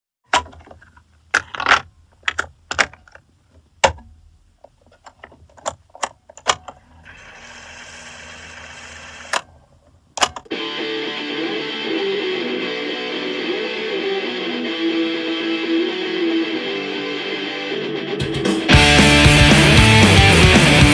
mladé klatovské punkové skupiny